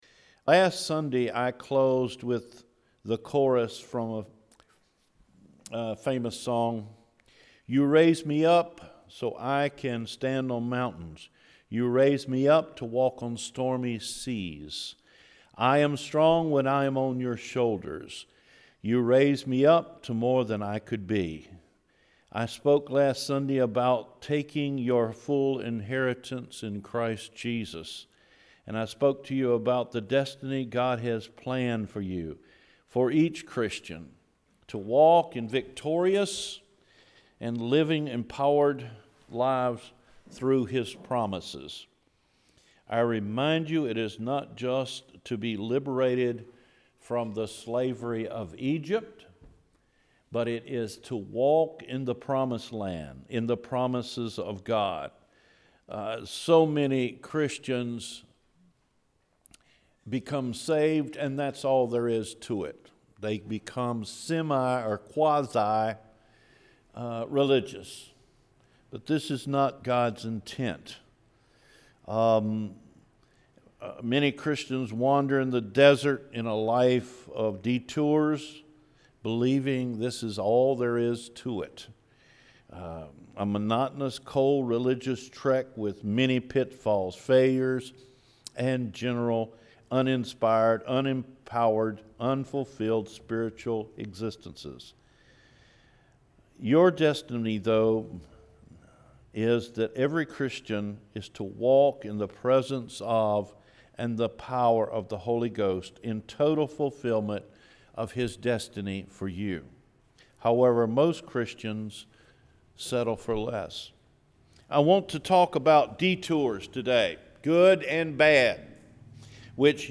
Walk in God’s Destiny: Good and Bad Detours – May 28 Sermon